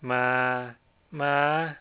In the picture below, three contrasting tones are presented.
Low-rising tone is followed by a high-rising tone. High-falling tone is followed by a high-rising tone.